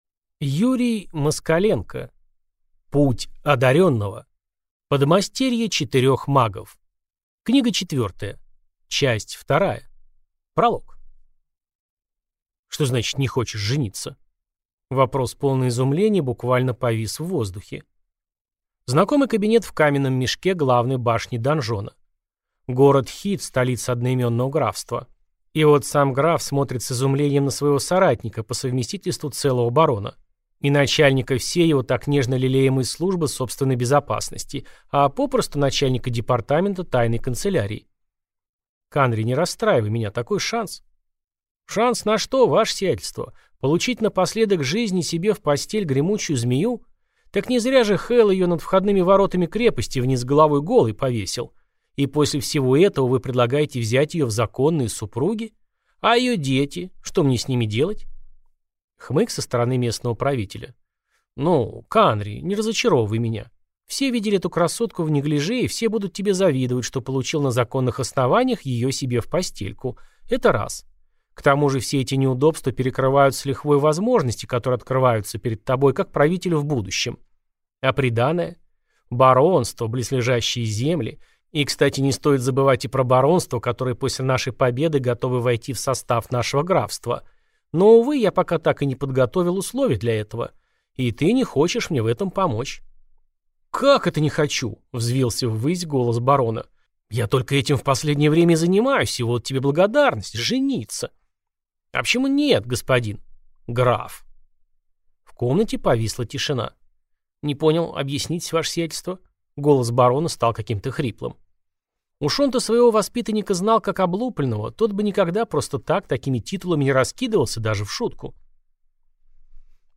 Аудиокнига Путь одарённого. Подмастерье четырёх магов. Книга четвёртая. Часть вторая | Библиотека аудиокниг